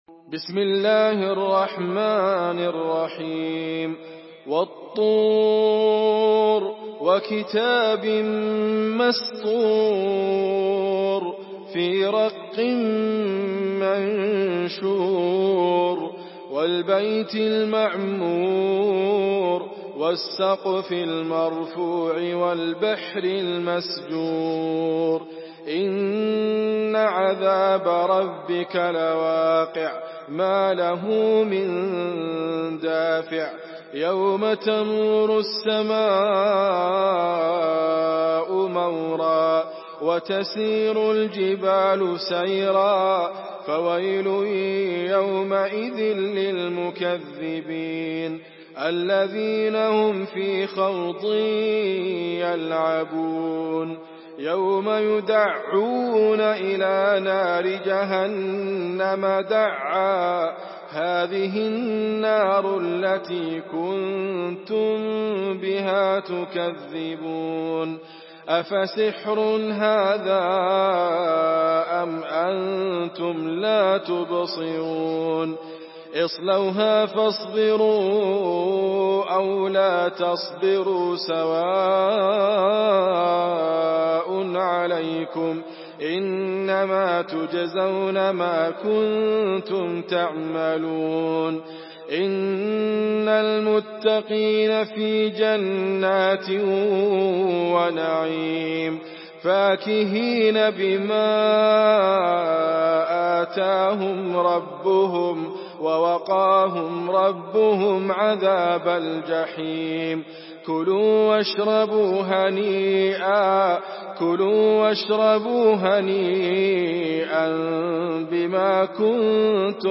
Surah الطور MP3 by إدريس أبكر in حفص عن عاصم narration.
مرتل حفص عن عاصم